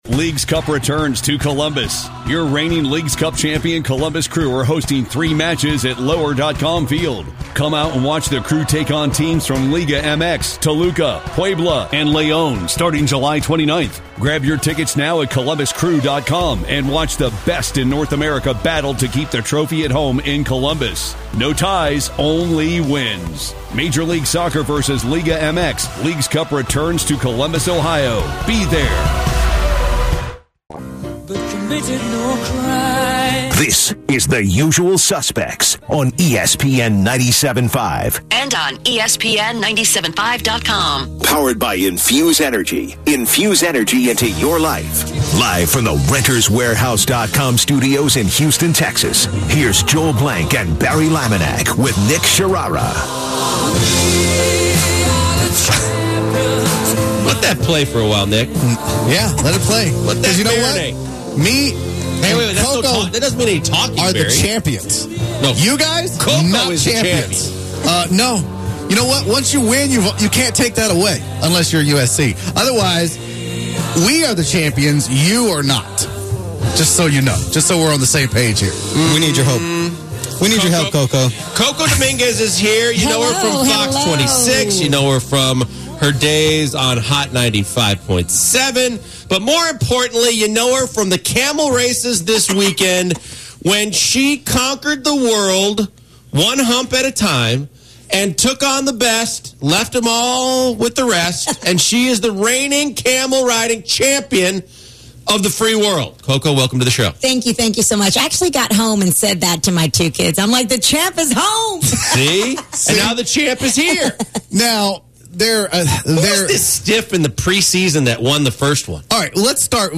Hear the friendly banter between the two.